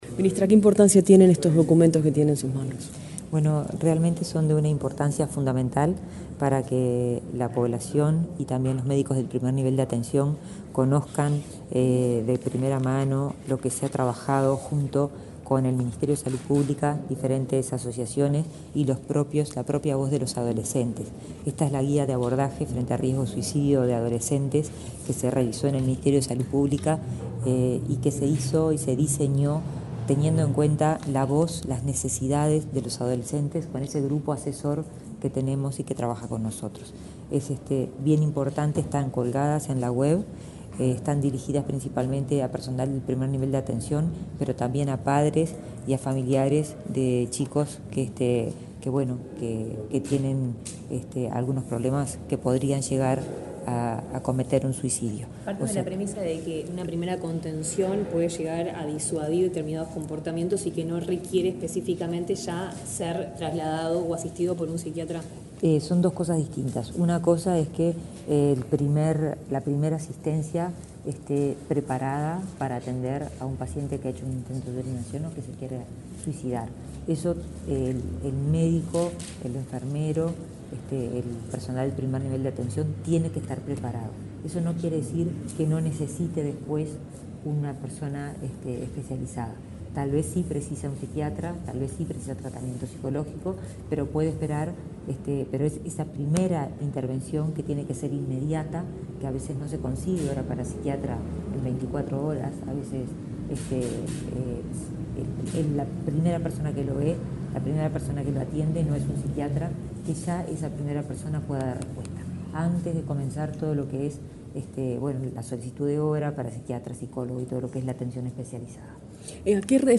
Declaraciones de la ministra de Salud Pública, Karina Rando
Luego, dialogó con la prensa.